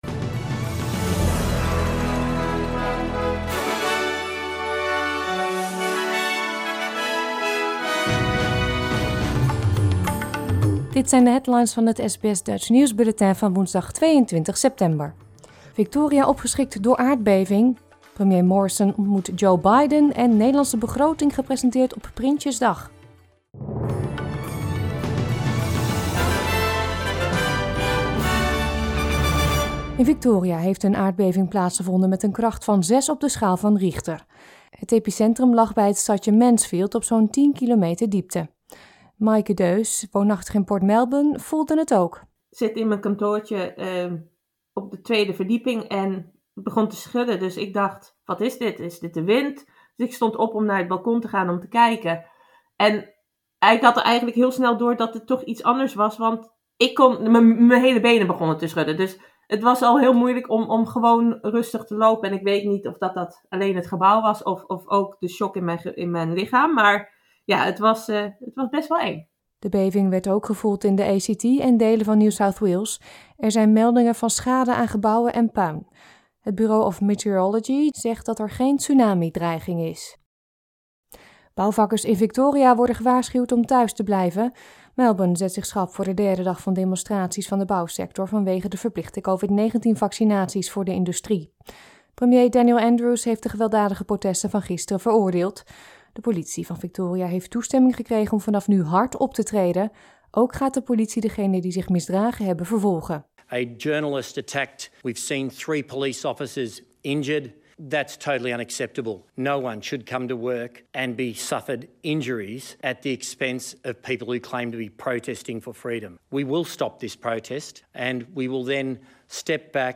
Nederlands/Australisch SBS Dutch nieuwsbulletin van woensdag 22 september 2021